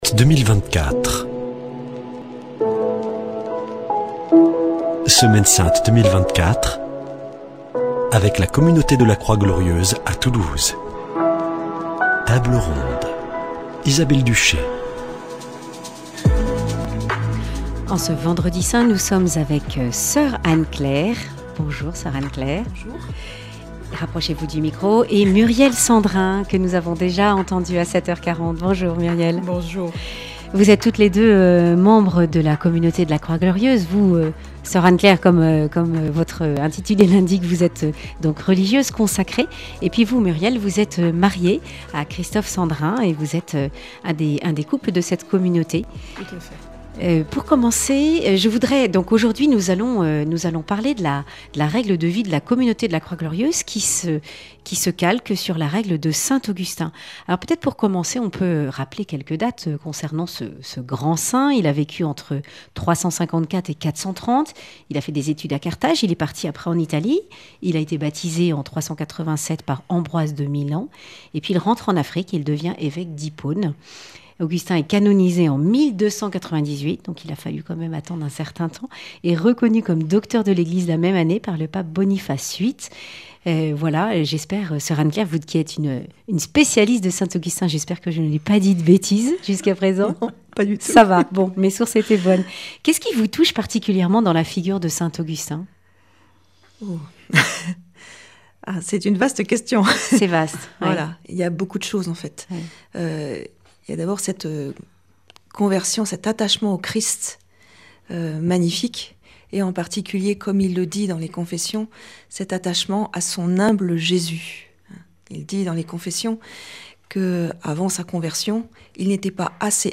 table_ronde_vendredi.mp3